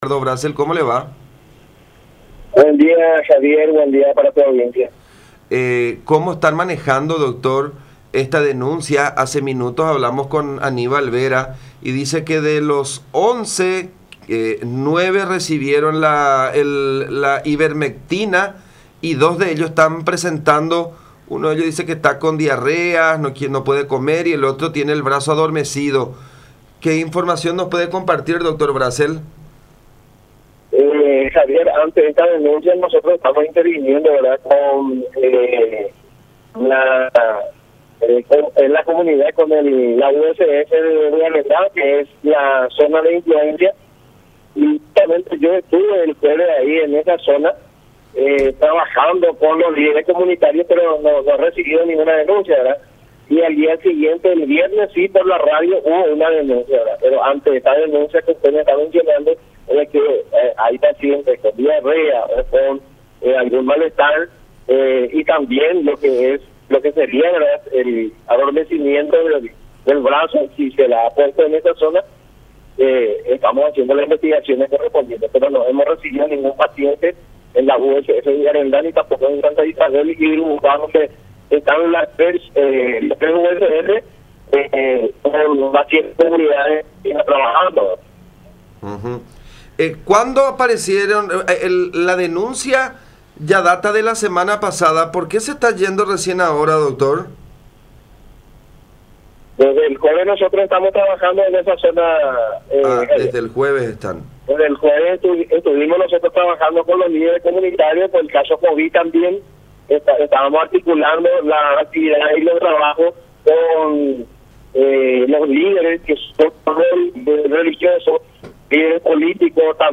“Nosotros ante la denuncia de los indígenas, estamos tomando intervención en el caso”, afirmó el Dr. Ricardo Brassel, director de la II Región Sanitaria (San Pedro), en contacto con La Unión.